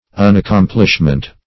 Search Result for " unaccomplishment" : The Collaborative International Dictionary of English v.0.48: Unaccomplishment \Un`ac*com"plish*ment\, n. The state of being unaccomplished.
unaccomplishment.mp3